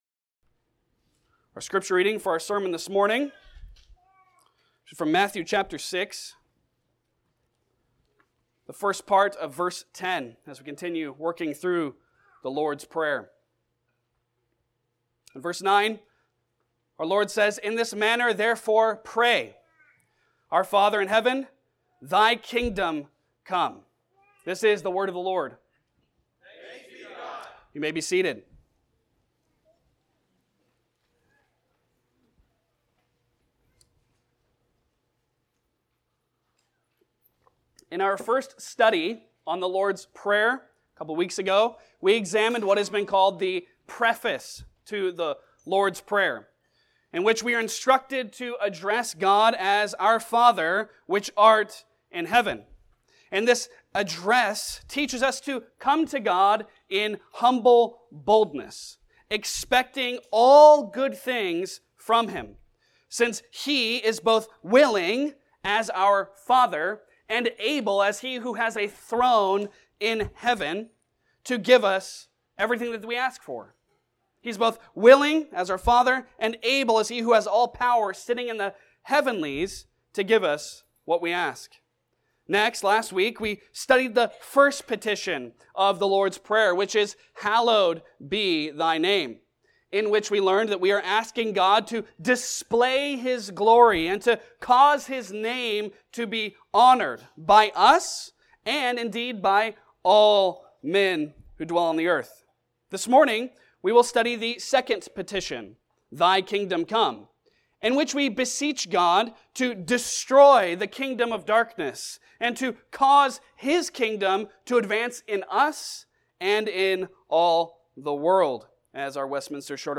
Passage: Matthew 6:10a Service Type: Sunday Sermon